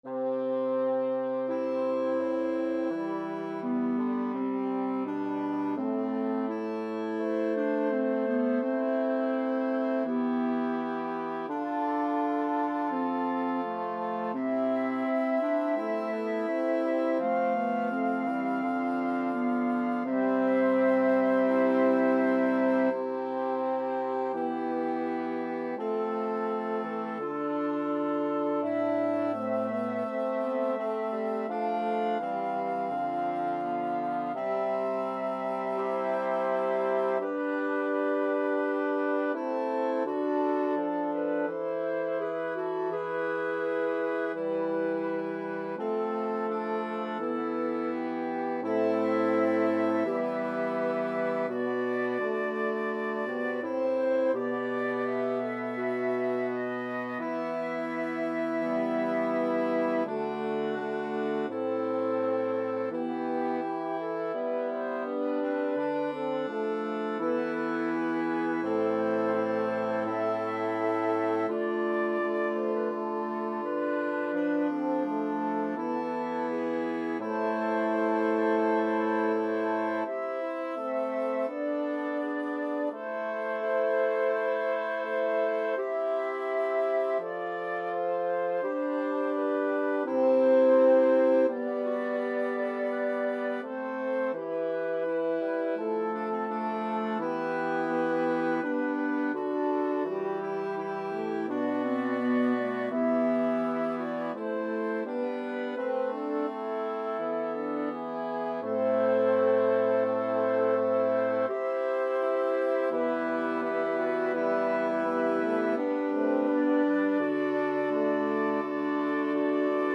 Number of voices: 8vv Voicing: SATB.SATB Genre: Sacred, Motet
Language: Latin Instruments: A cappella